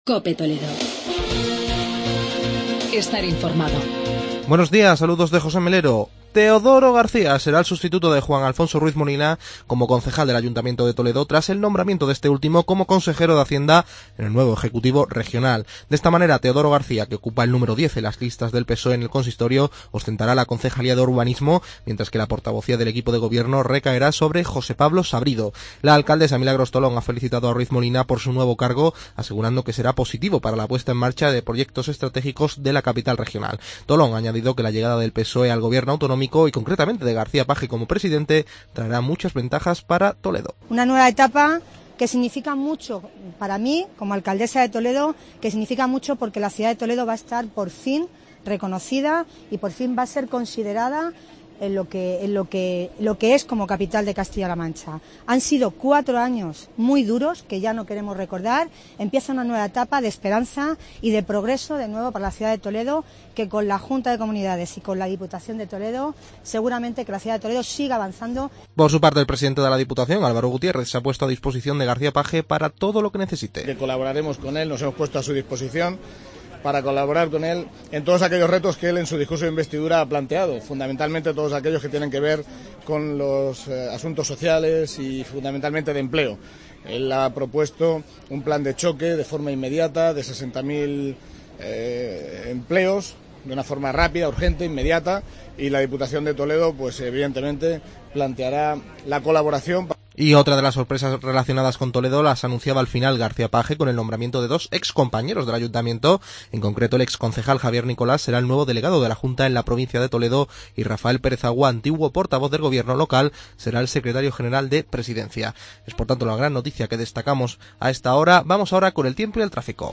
Informativo provincial